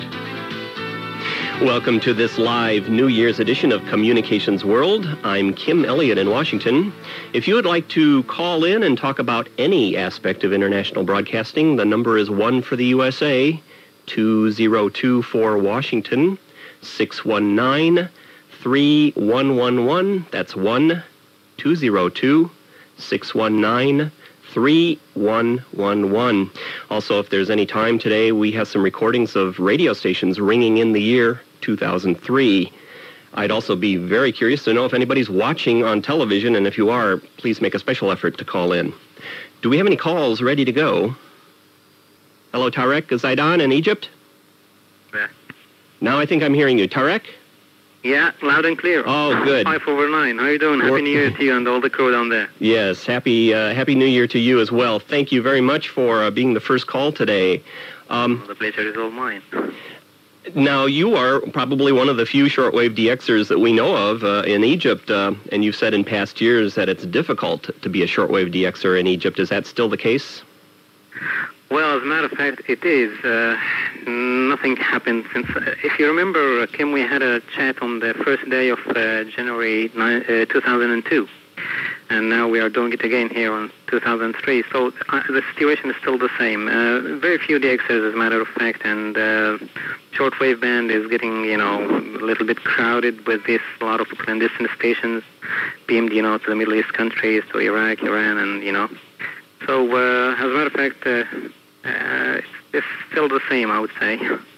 Presentació del programa dedicat a les comunicacions i trucada telefònica a un radioescolta d'Egipte
FM